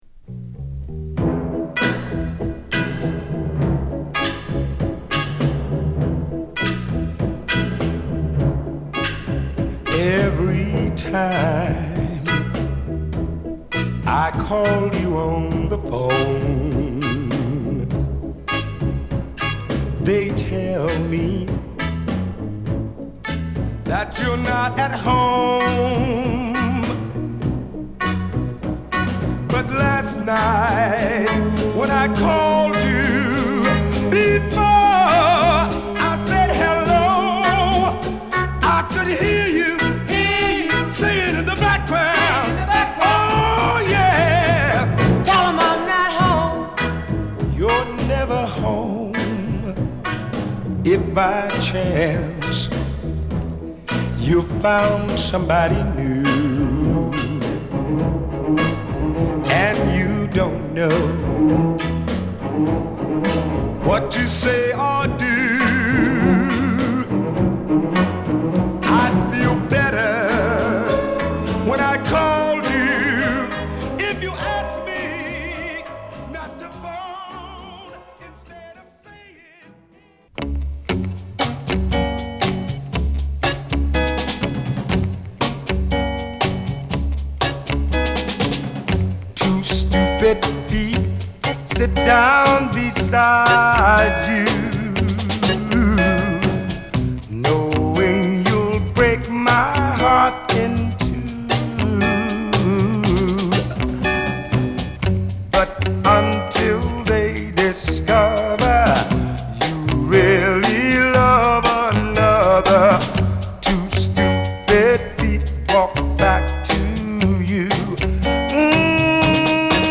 Soul / Funk